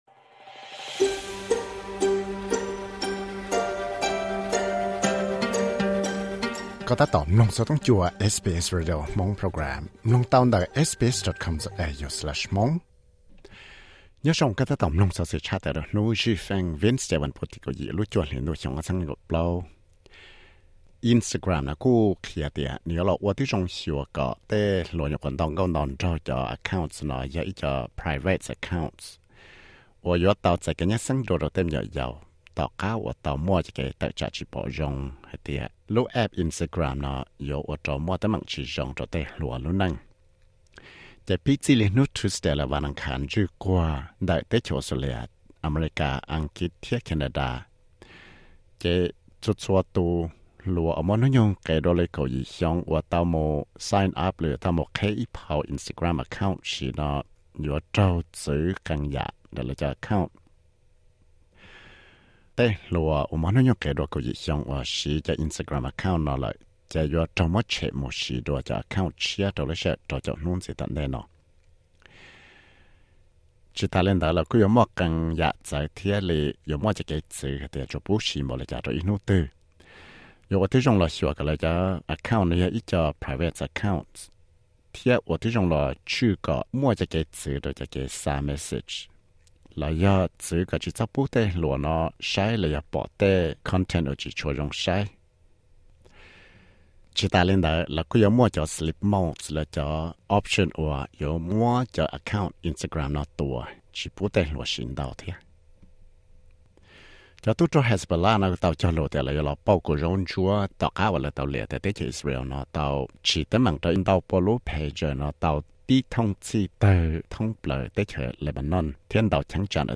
Xov xwm luv Credit